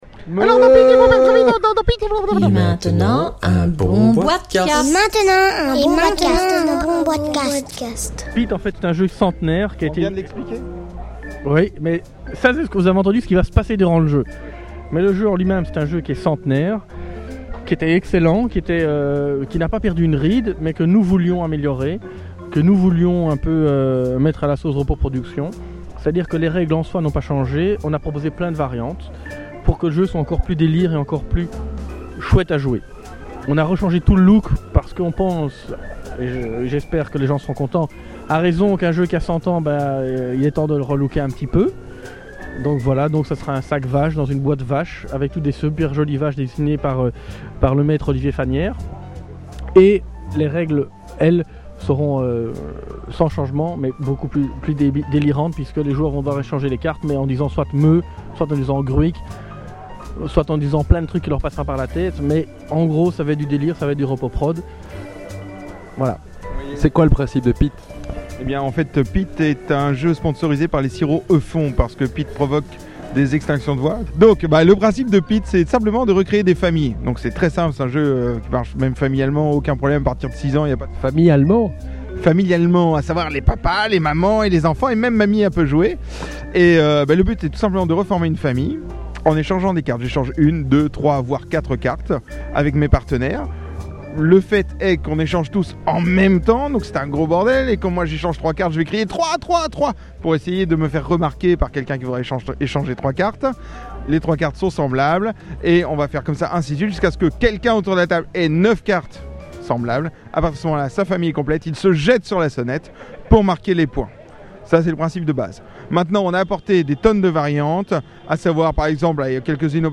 Enregistré le 26 juin 2008 au Chaff – Bruxelles.